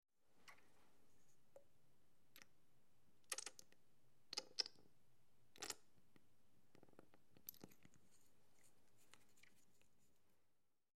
На этой странице собраны звуки капельницы в разных вариантах: от монотонного стекания жидкости до фонового шума больничной палаты.
Звук нажатия на медицинскую капельницу